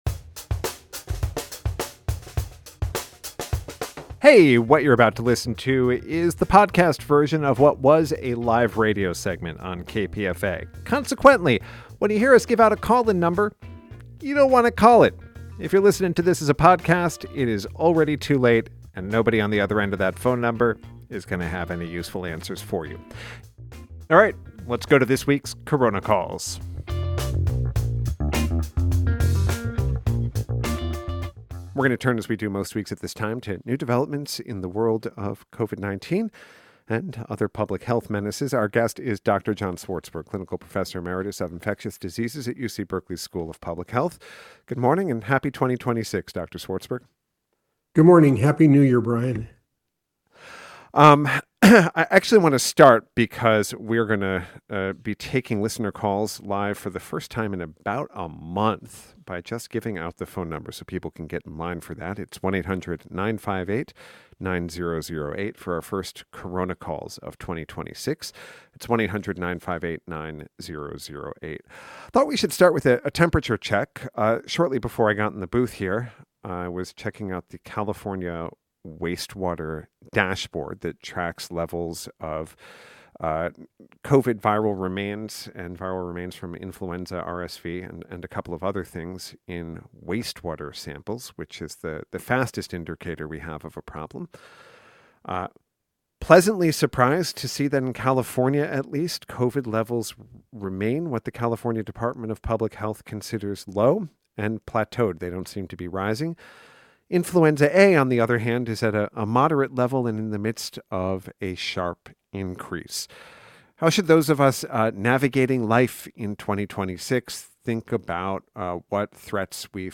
Recorded March 19, 2024 at Book Passage Bookstore in Corte Madera, California.